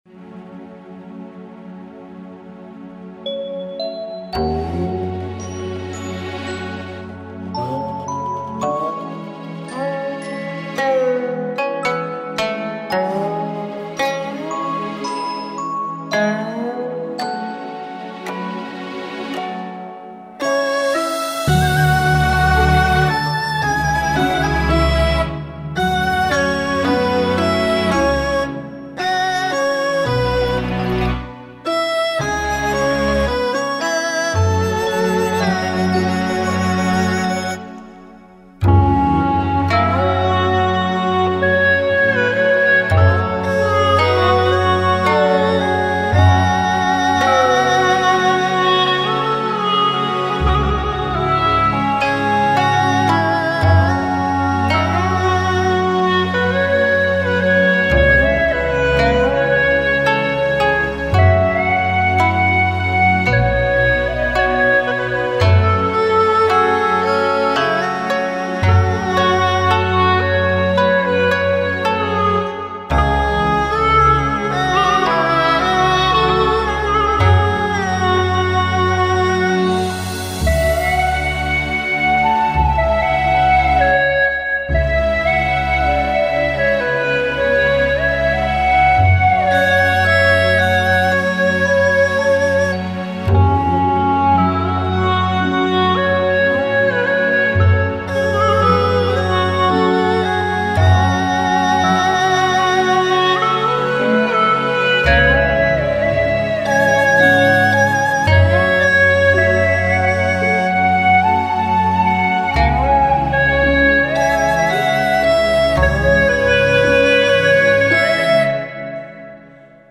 如泣如诉！
好听，哀婉动人！